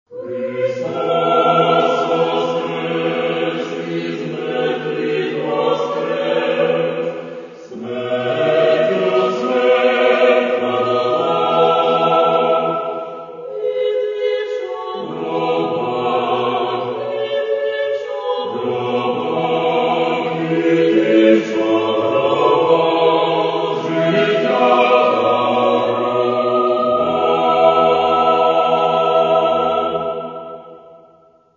Церковна
На цьому диску представлені пасхальні співи.